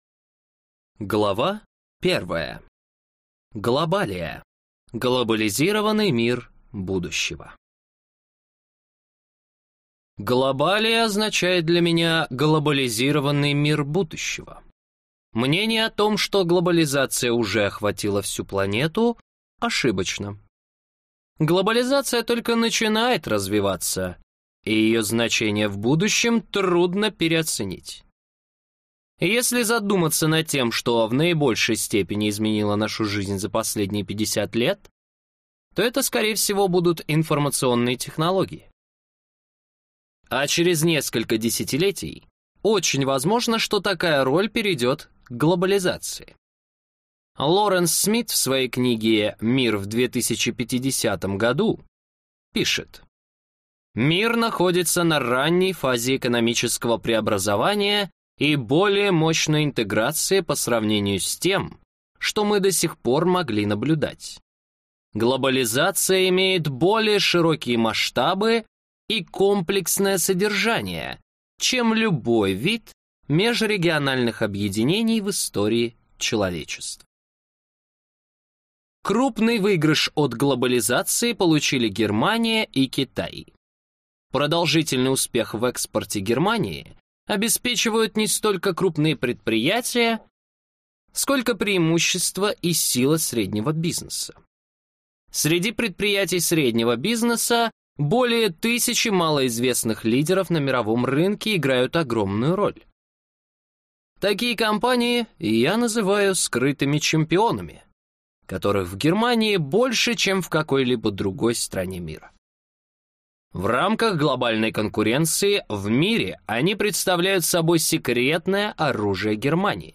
Аудиокнига Скрытые чемпионы – прорыв в Глобалию. Почему немецкие компании доминируют в мире | Библиотека аудиокниг